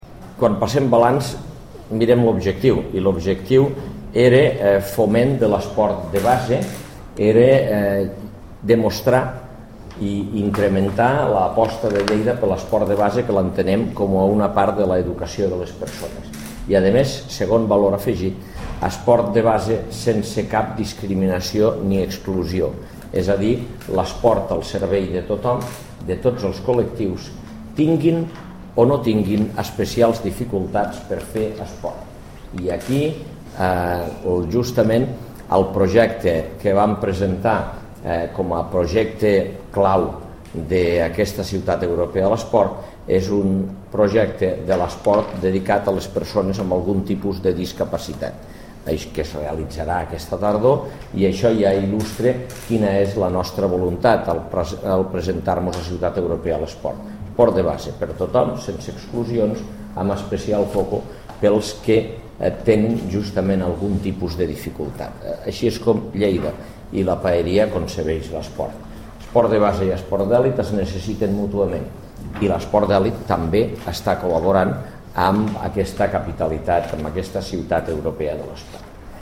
tall-de-veu-alcalde-ciutat-europea-de-lesport